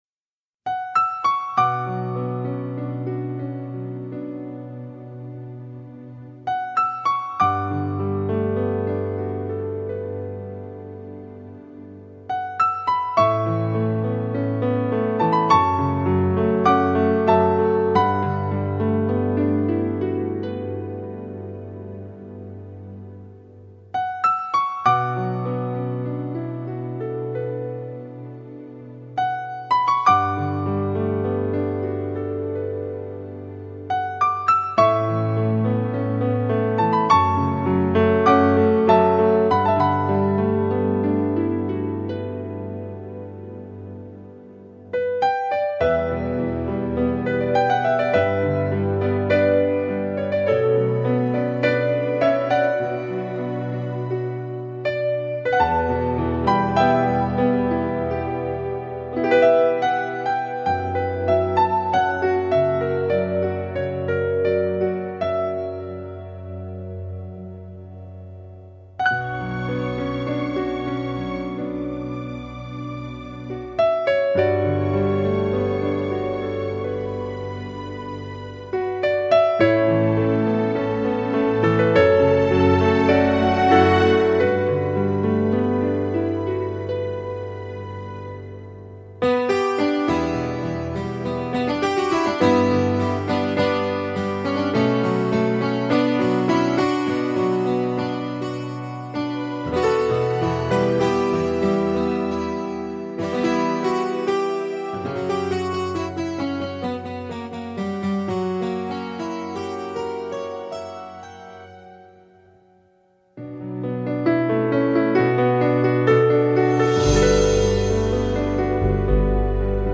Музыка вечной памяти